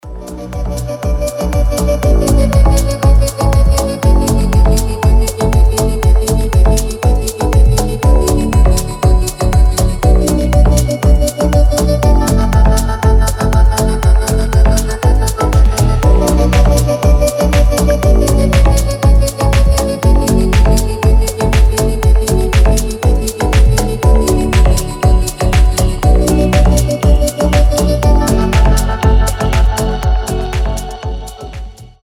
deep house
атмосферные
мелодичные
женский голос
Electronic
мистические
Атмосферная музыка в стиле deep house